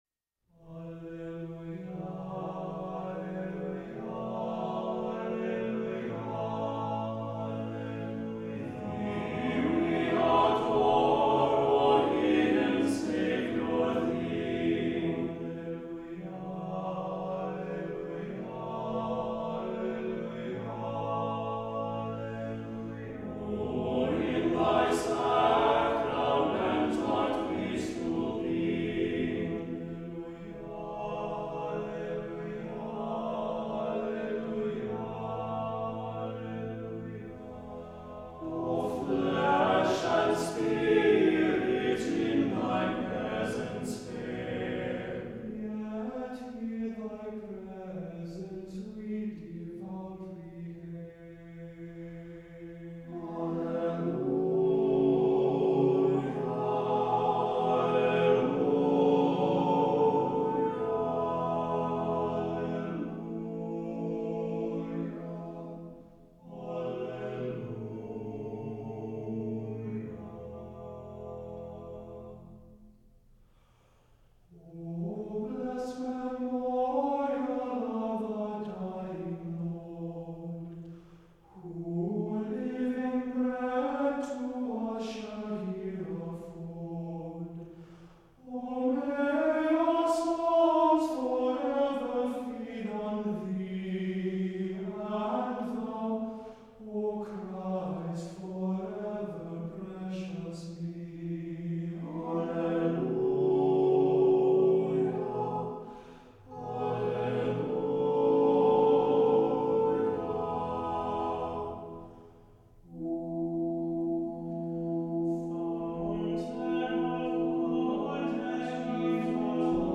SSSAAA/TTBB a cappella